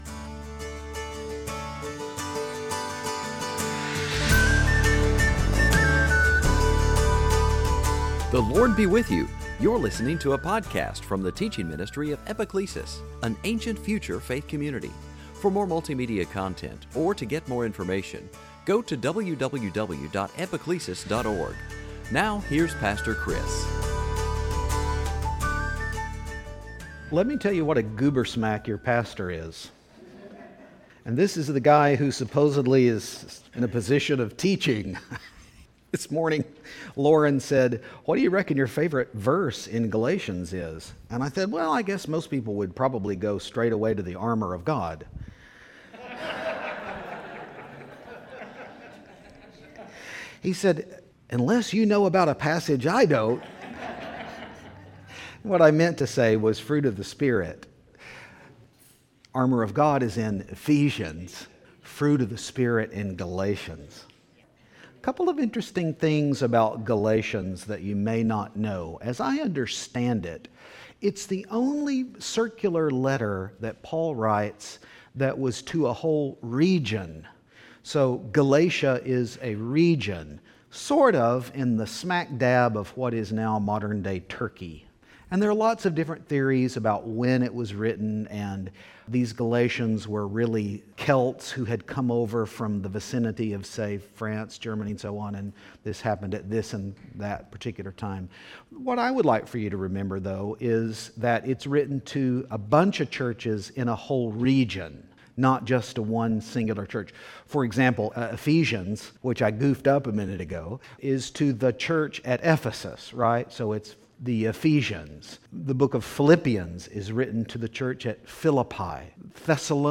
Service Type: Season after Pentecost